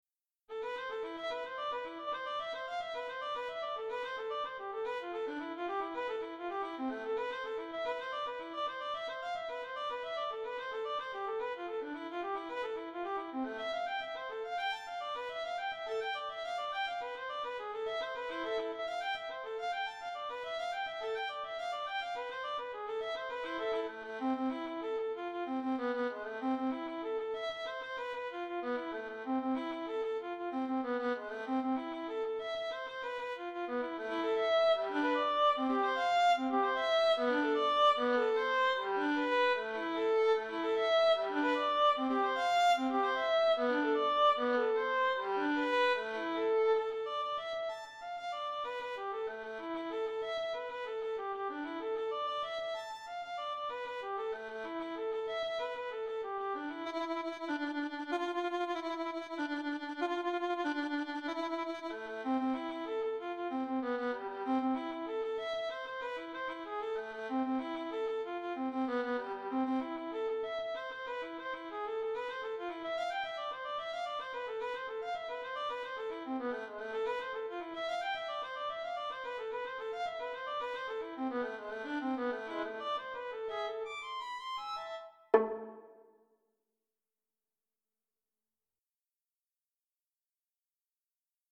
Caprice for Solo Violin No. 4